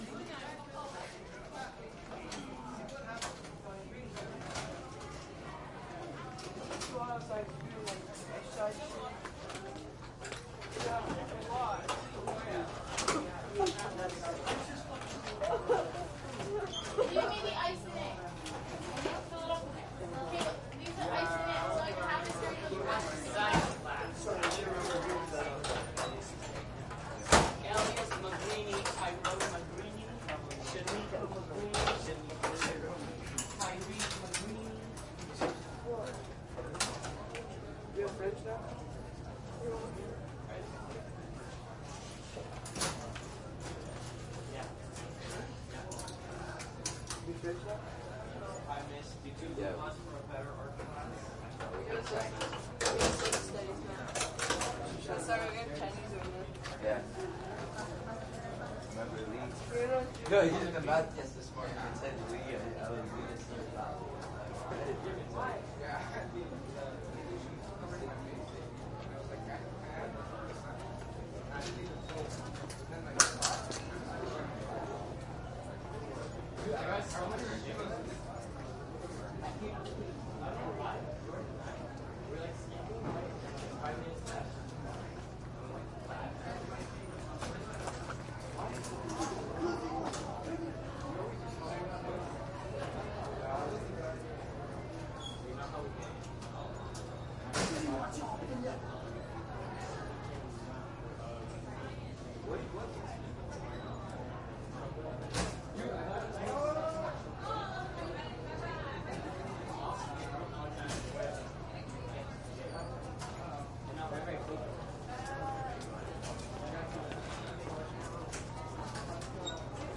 高中" 人群在高中走廊的灯光下积极交谈1
描述：人群int高中走廊光活跃的对话1
标签： 重量轻 INT 人群 学校 走廊
声道立体声